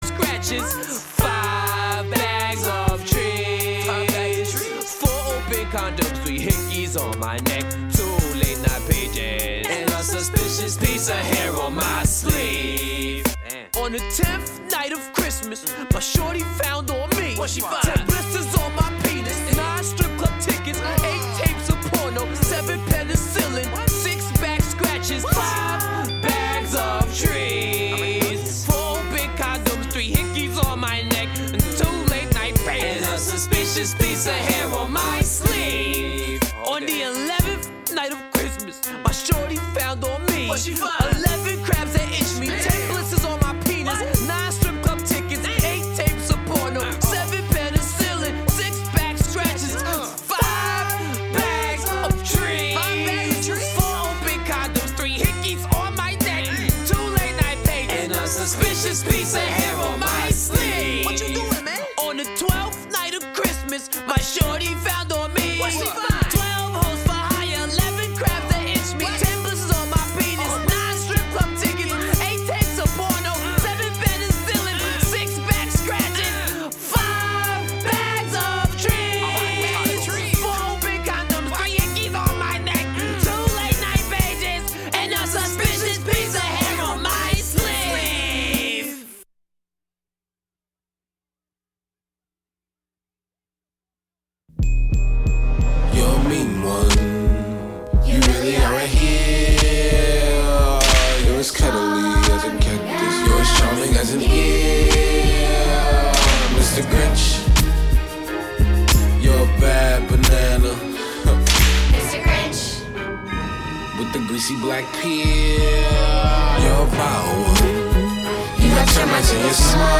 New and throwbacks, from Portland and beyond. Plus, catch interviews from talented artists.